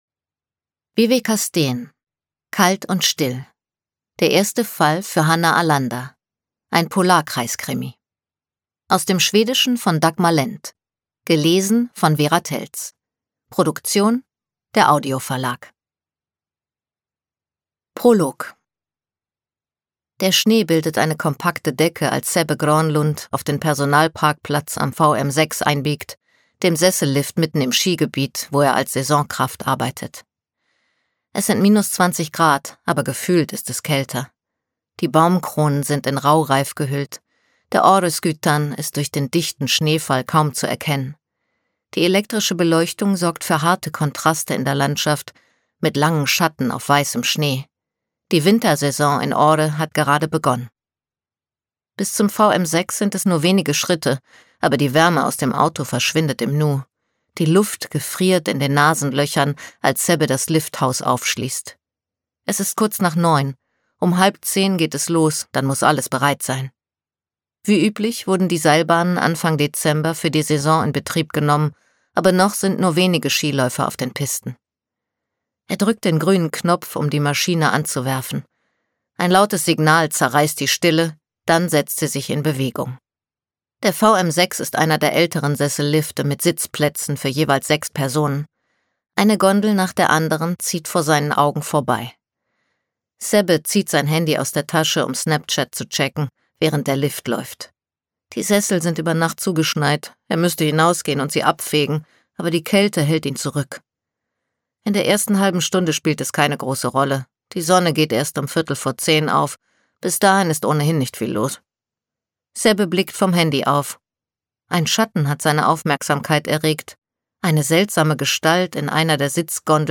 Themenwelt Literatur Krimi / Thriller / Horror Krimi / Thriller